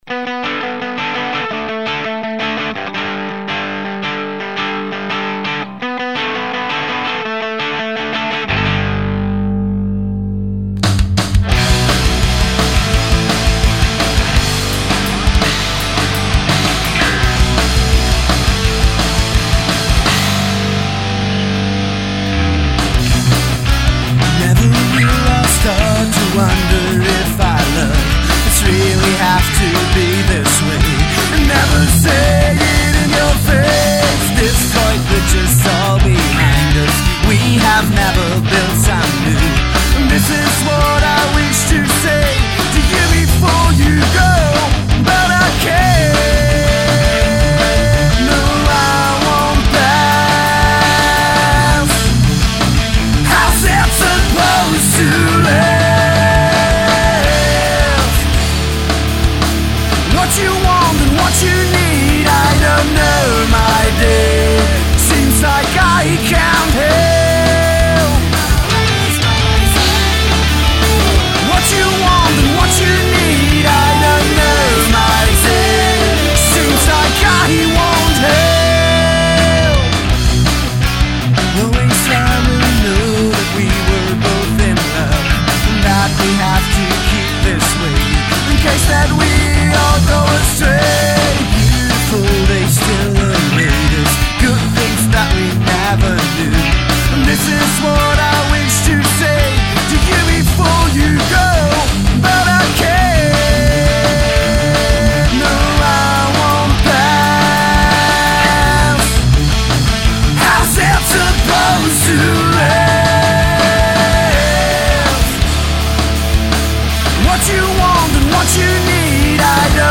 Emorock
Vocals
Vocals/Gitarre
Bass
Drums